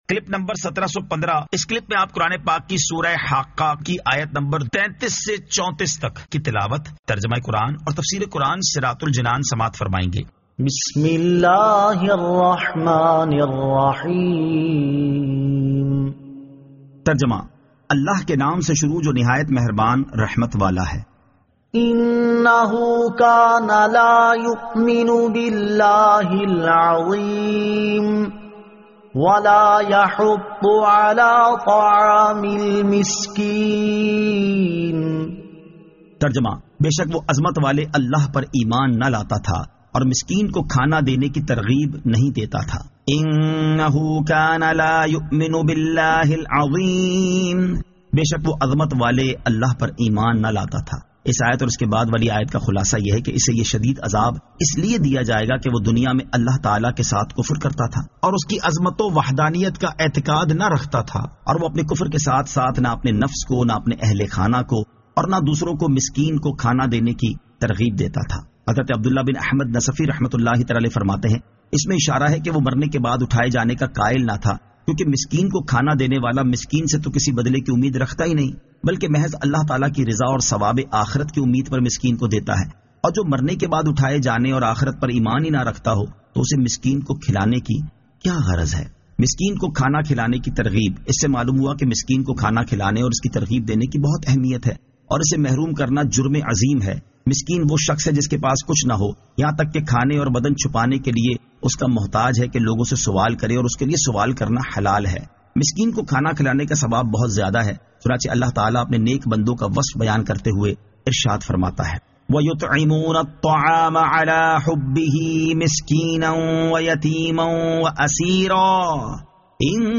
Surah Al-Haqqah 33 To 34 Tilawat , Tarjama , Tafseer